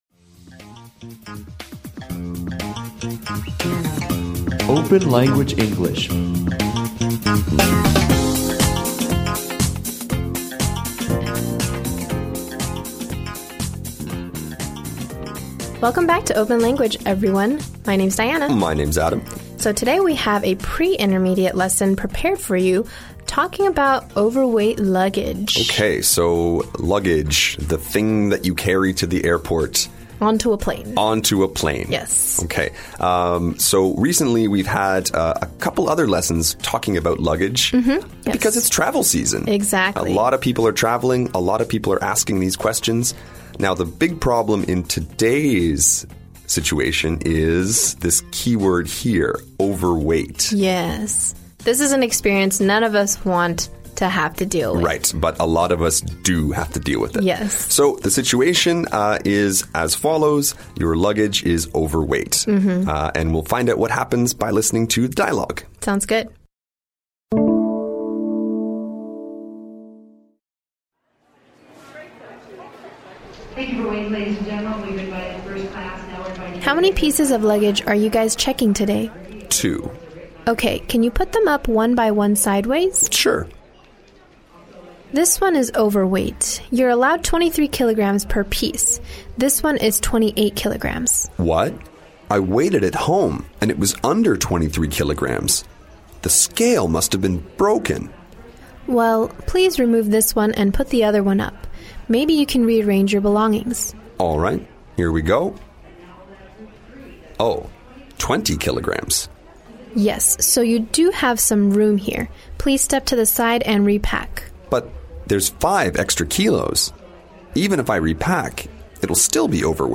本期为大家推荐一节B1等级的会员课程：机场托运行李
我们剪取了部分课程精华，大家可以感受下。